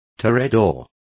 Complete with pronunciation of the translation of toreador.